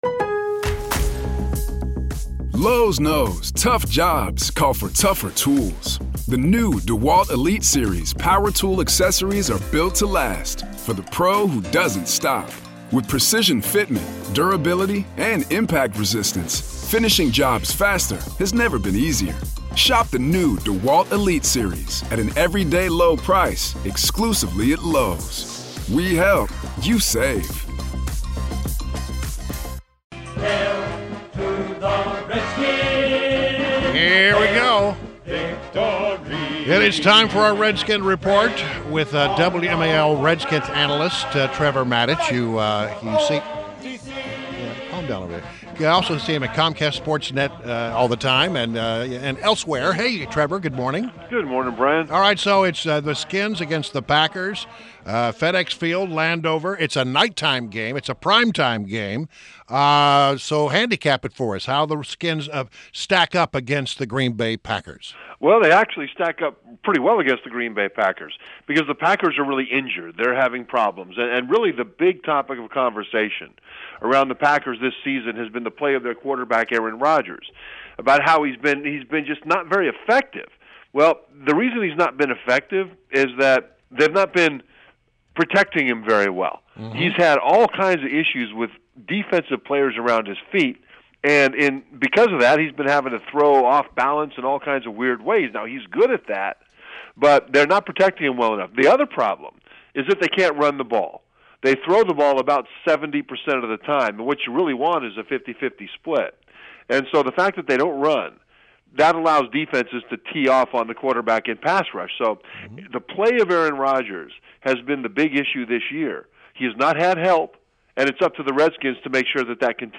INTERVIEW — TREVOR MATICH — Redskins elite long snapper, WMAL’s Redskins analyst and Comcast SportsNet co-host